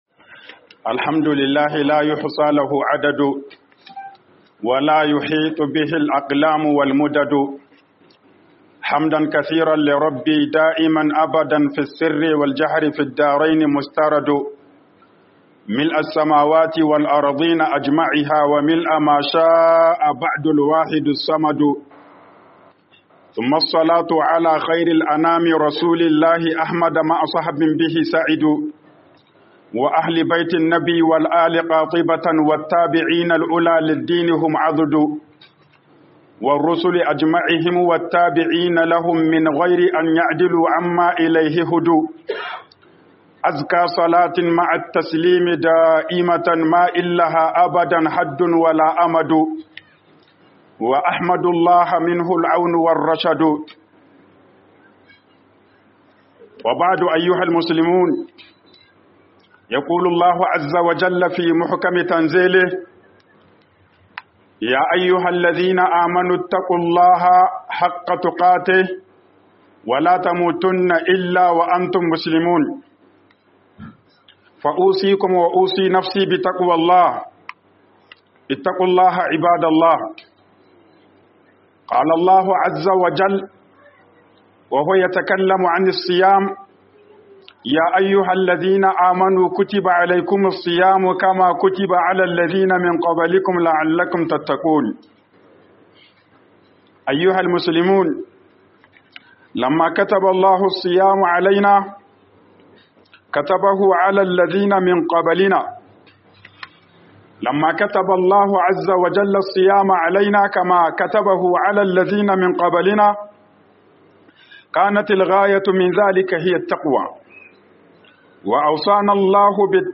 HUDUBAR JUMA'A 29.03.2024